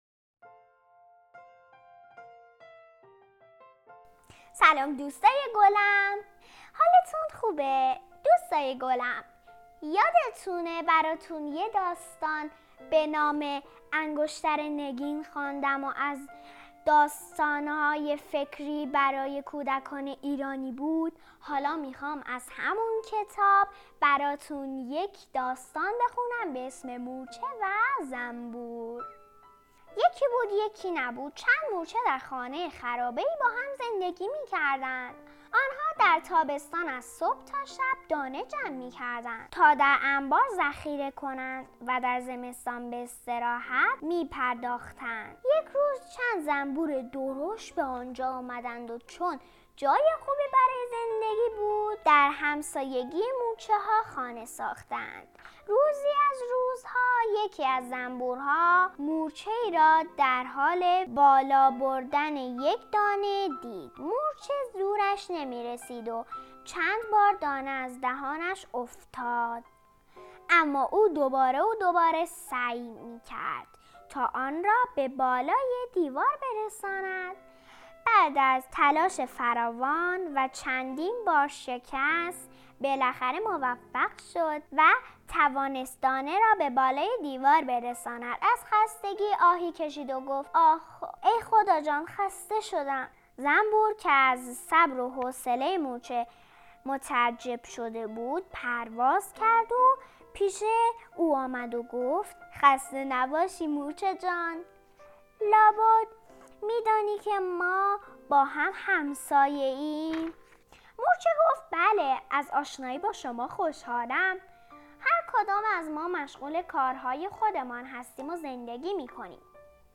• قصه کودکانه داستان مورچه و زنبور